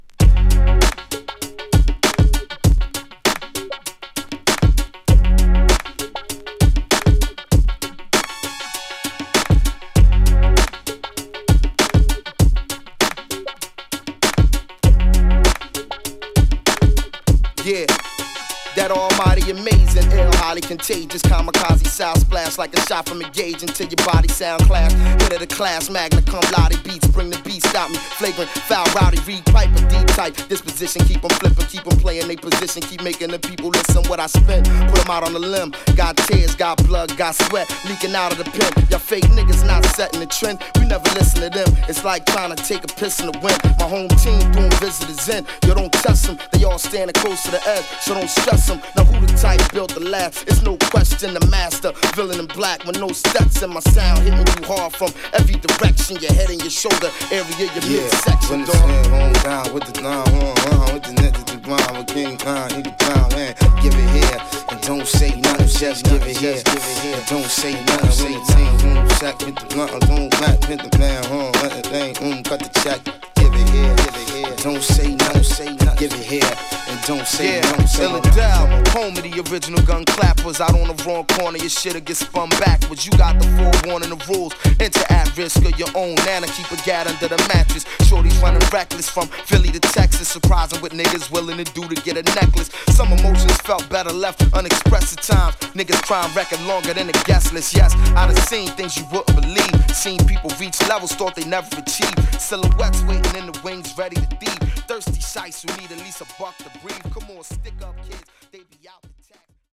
サウンドは硬質なキックと弾けるようなスネアがコユいドラムス上でスリリングなシンセのリフが交差する